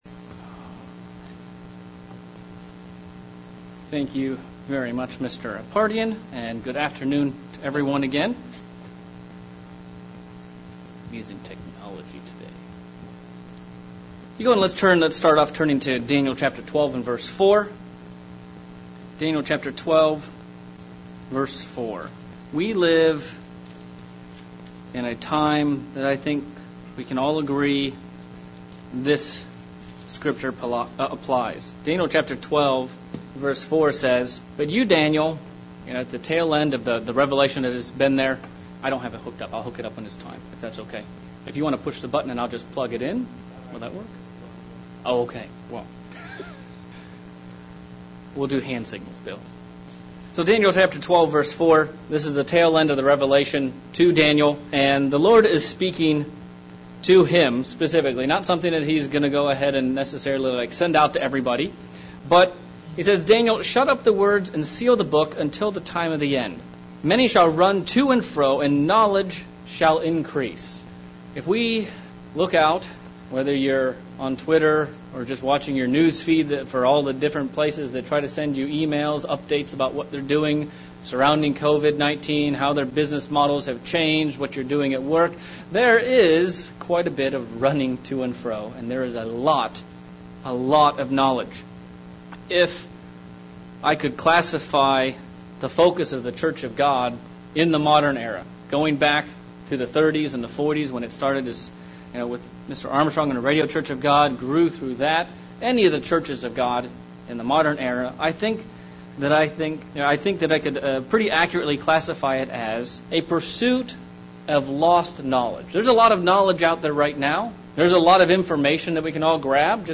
Sermon examining Knowledge. Is Knowledge all that matters or is doing something with it more important?